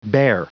Prononciation du mot bare en anglais (fichier audio)
Prononciation du mot : bare